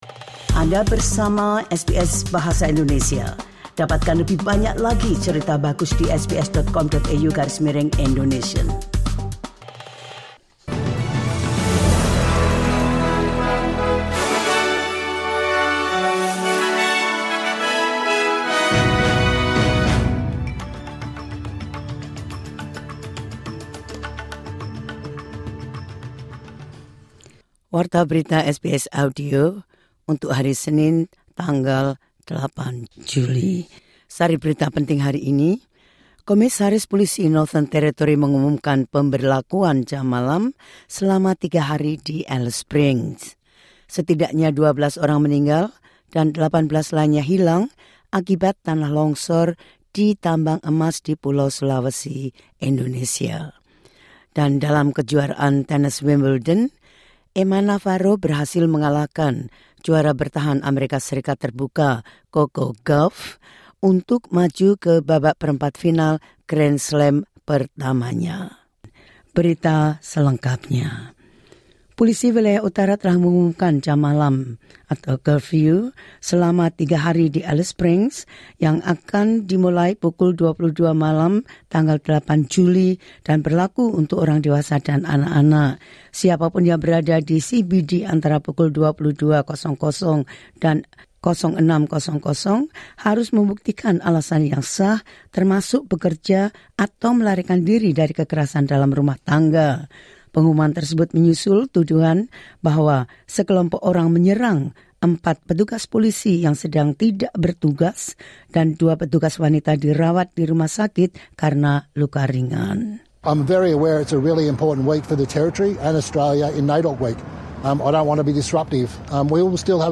The latest news of SBS Audio Indonesian program – 08 Jul 2024.